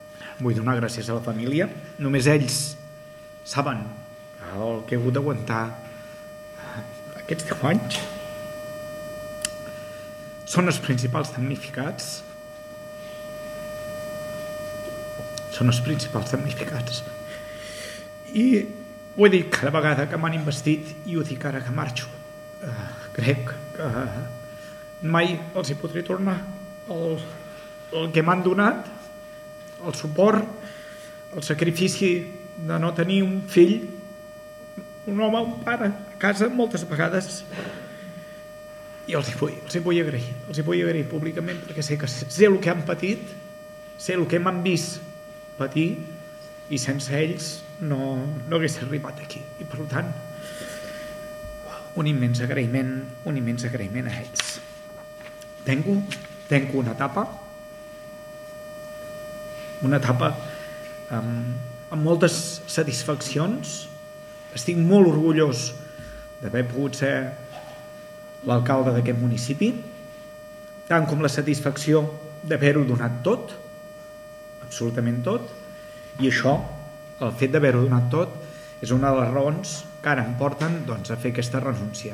Durant la roda de premsa Lluís Puig ha destacat la feina feta per l’equip de Govern durant aquests darrers 10 anys de mandat.
L’alcalde s’ha mostrat del tot emotiu quan ha hagut d’agraïr la feina feta per l’equip de Govern, els treballadors de l’ajuntament i la seva família.